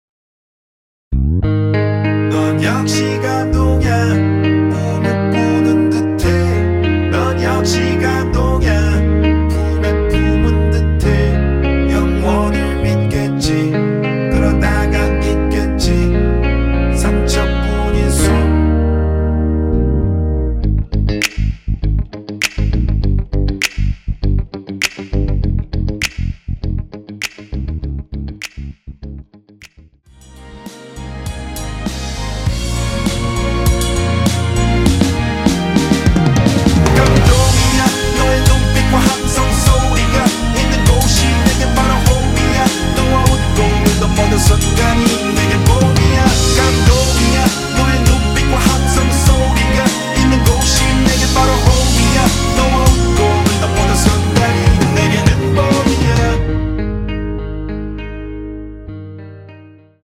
원키에서(-1)내린 코러스 포함된 MR입니다.(미리듣기 확인)
Ab
앞부분30초, 뒷부분30초씩 편집해서 올려 드리고 있습니다.
중간에 음이 끈어지고 다시 나오는 이유는